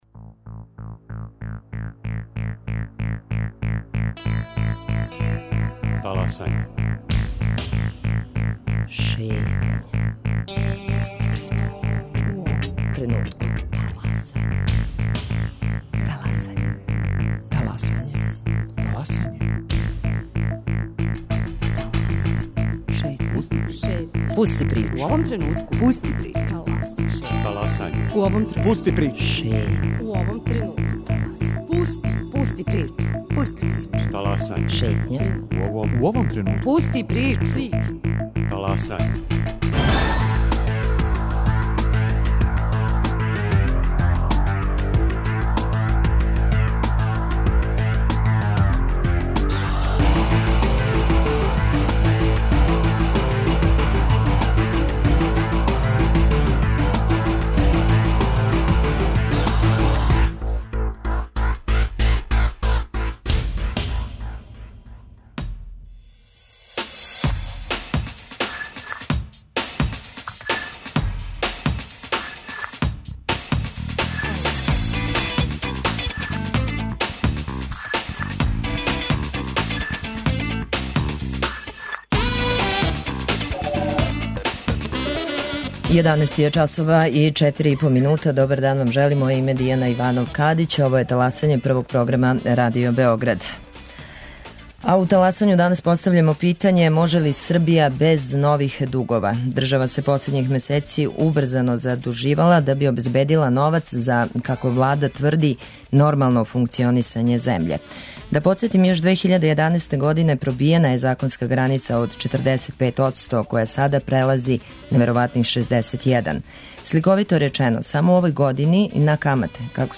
Гости: Дејан Шошкић, бивши гувернер Народне банке Србије и Владимир Вучковић, члан Фискалног савета.